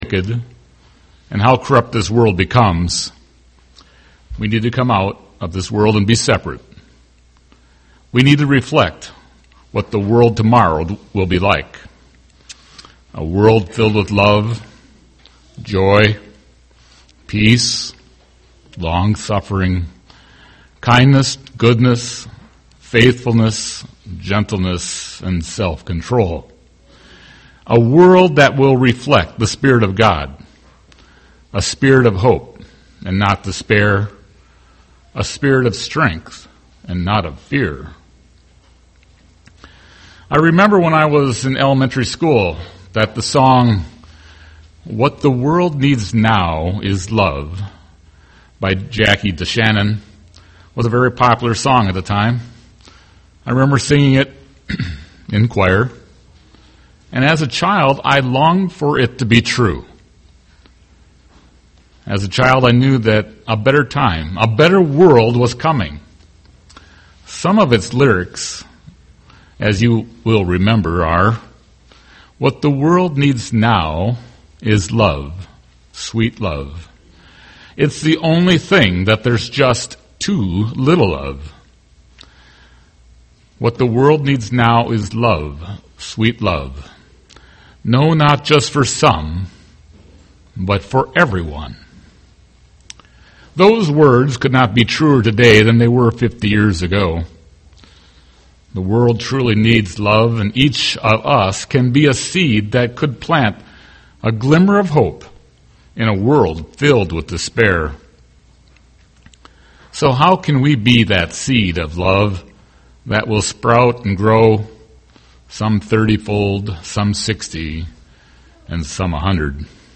What are the true characteristics of love? love one another UCG Sermon Studying the bible?